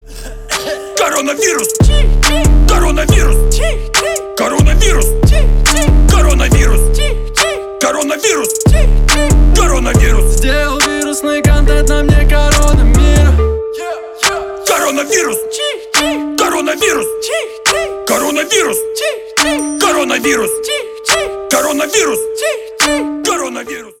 Рэп и Хип Хоп
громкие # весёлые